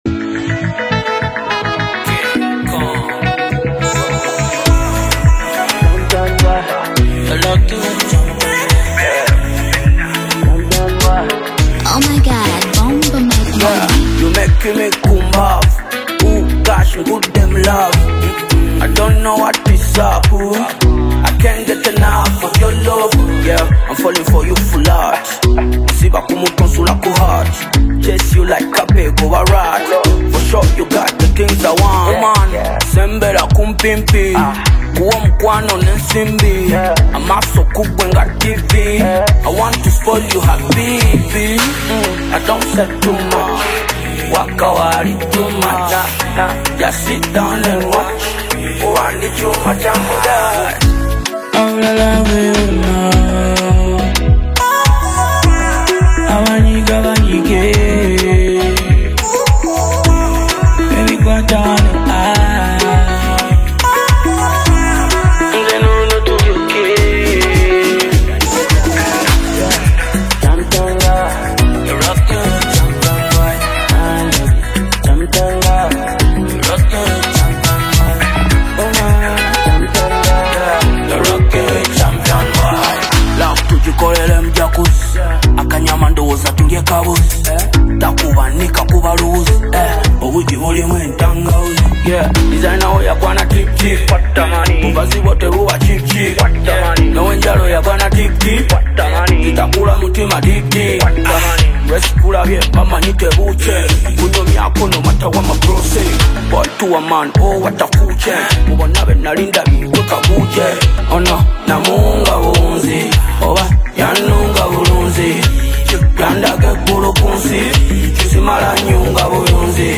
pulsing with a rhythmic beat
a pulsing rhythm